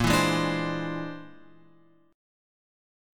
A# 9th Flat 5th